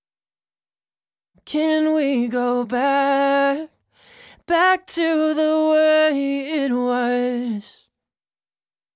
Lines – 80BPM – 01
Unison-Lines-80bpm-01-G-Minor-B-Major.mp3